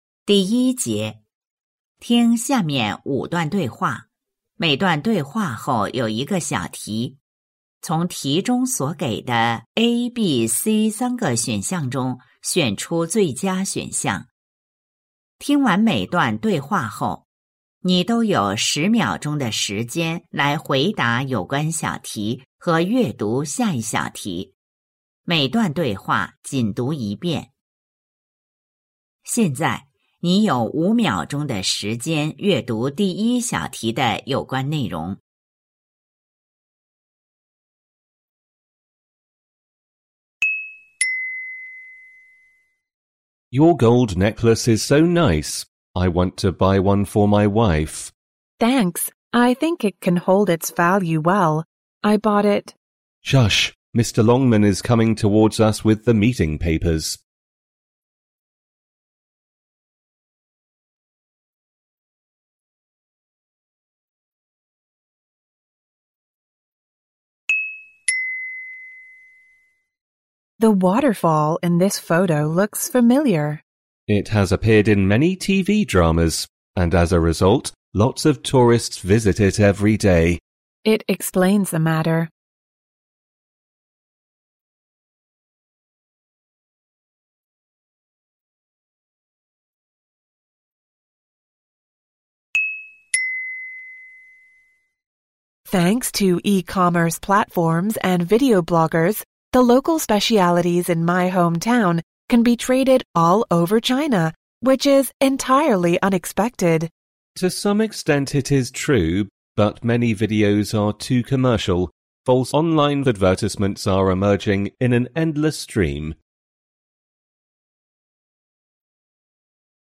树德中学2025届高三上学期期中考试英语听力.mp3